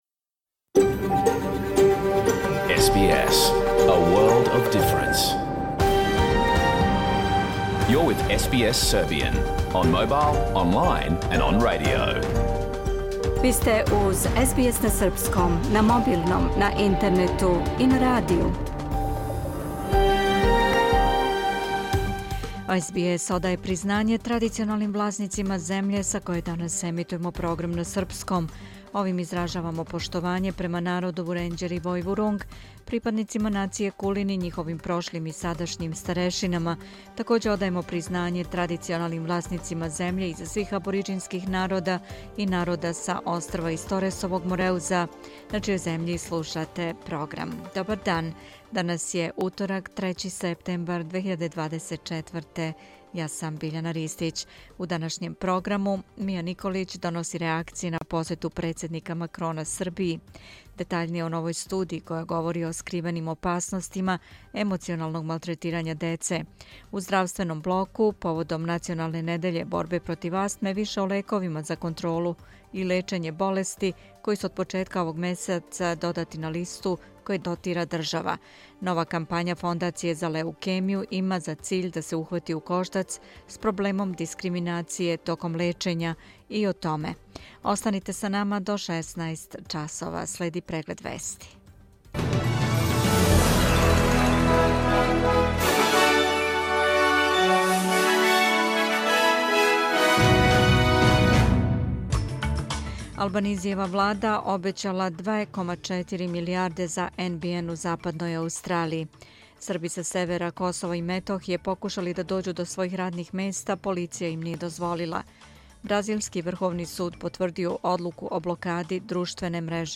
Програм емитован уживо 3. септембра 2024. године